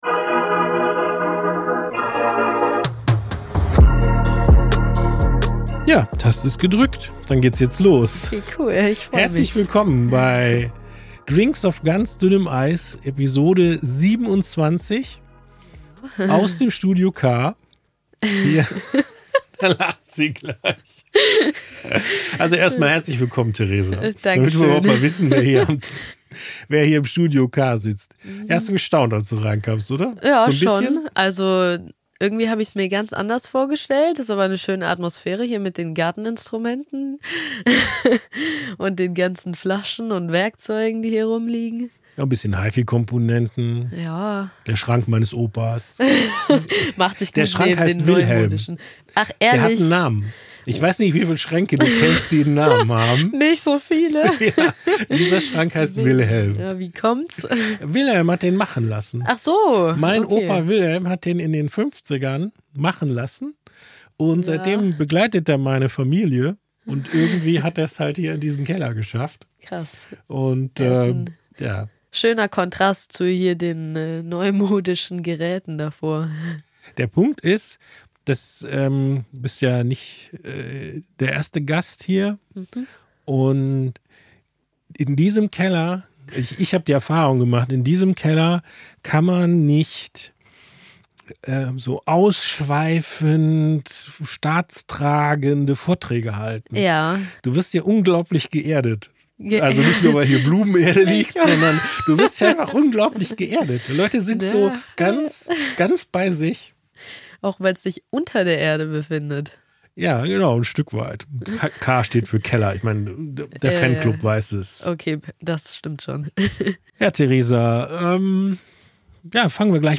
eine Sportstudentin im Studio K.